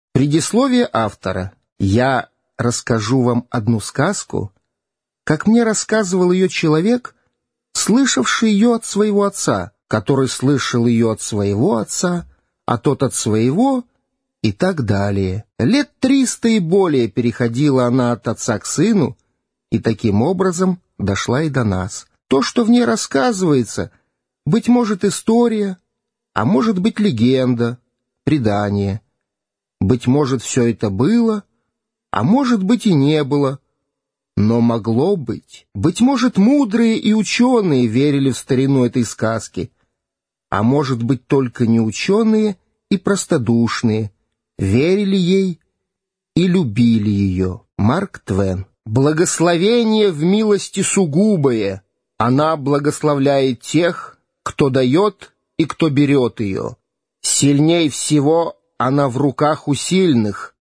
Аудиокнига Принц и нищий | Библиотека аудиокниг